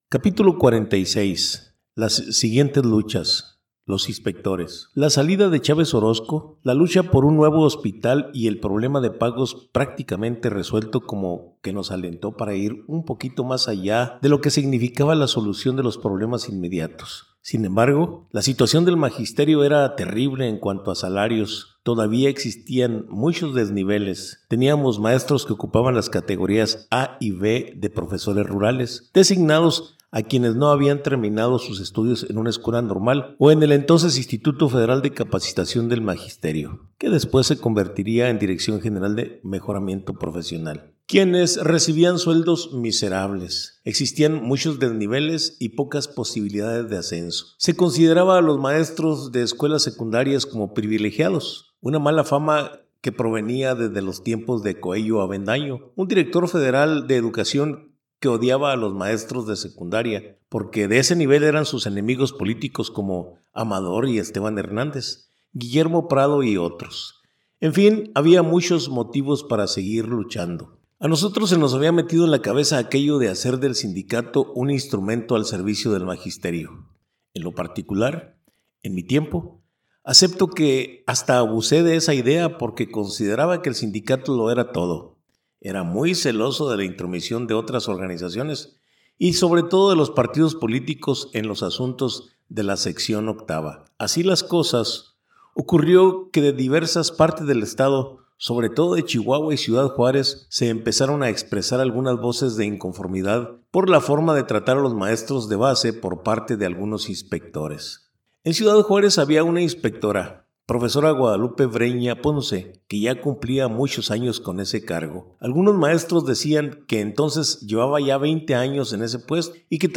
AUDIOLIBRO